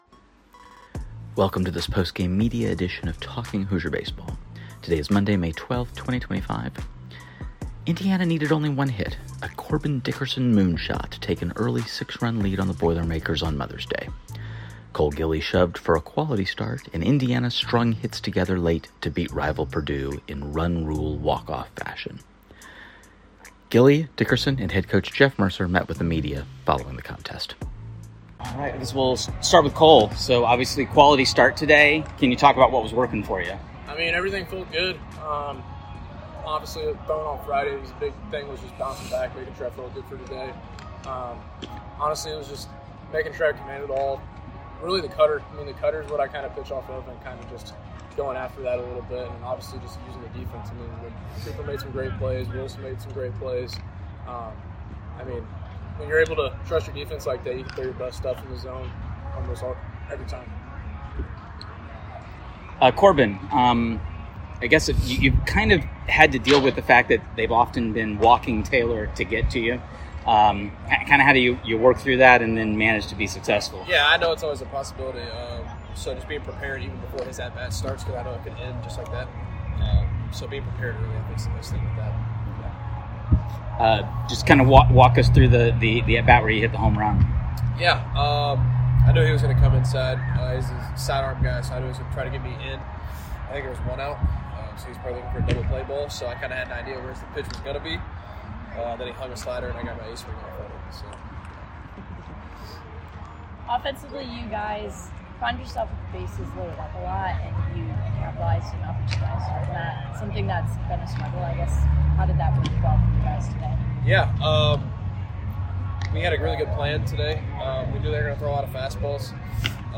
Postgame Media Purdue Mother’s Day Sunday